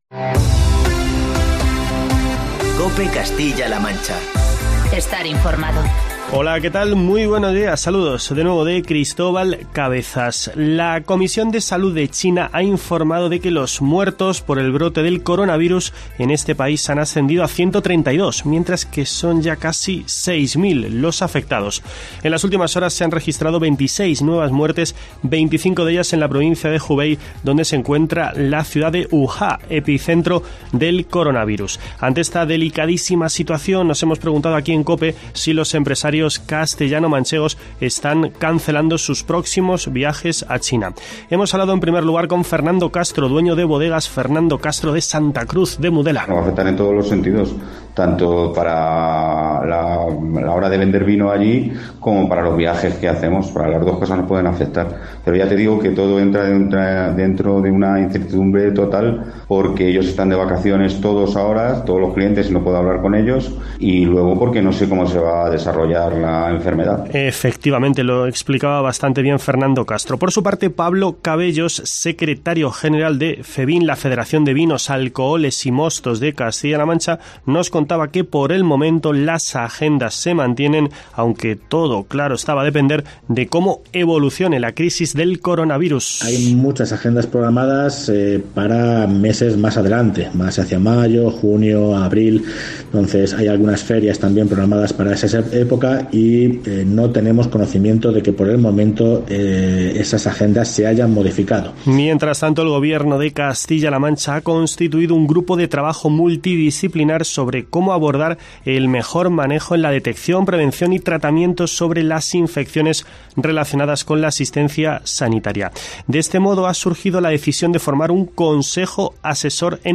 Escucha en la parte superior de esta noticia el informativo matinal de COPE Castilla-La Mancha y COPE Toledo.